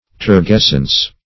Search Result for " turgescence" : The Collaborative International Dictionary of English v.0.48: Turgescence \Tur*ges"cence\, Turgescency \Tur*ges"cen*cy\, n. [Cf. F. turgescence.